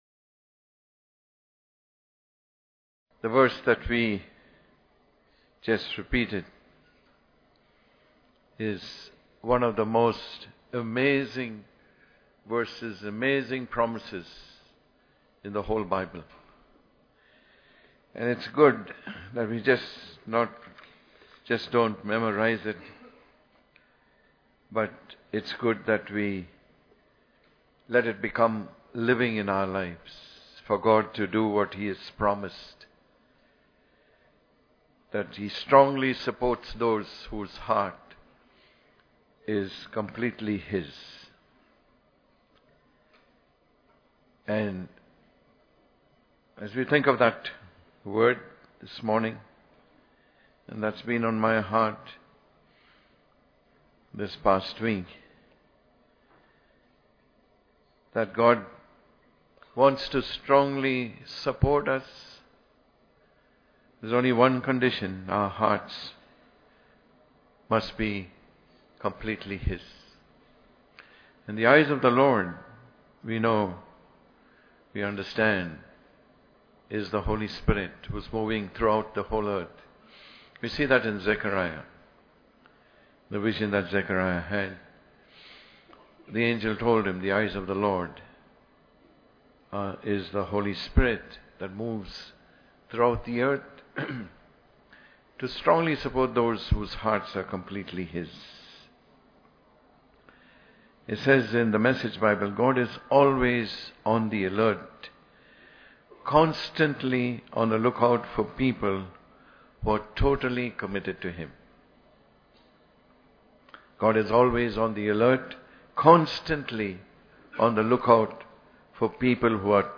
God Strongly Supports Those Totally Committed To Him Watch the live stream of the Sunday service from CFC Bangalore.